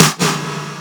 Clap 8.wav